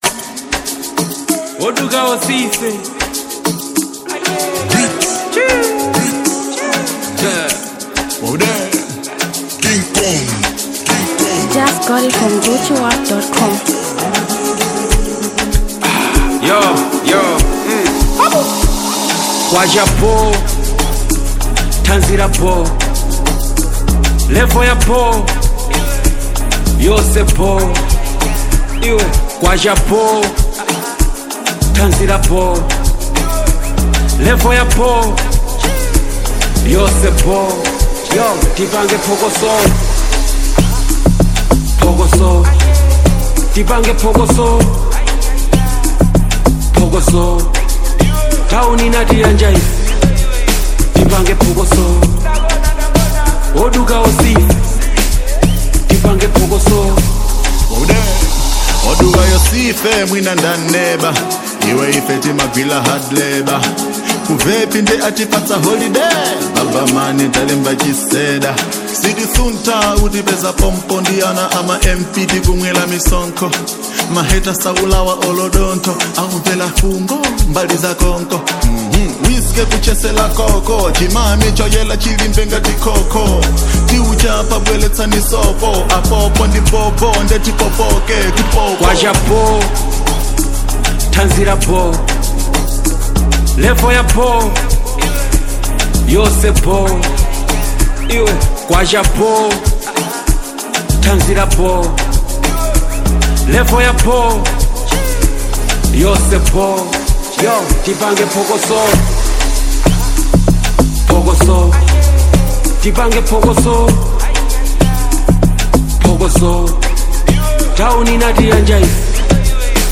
Malawian afro-beat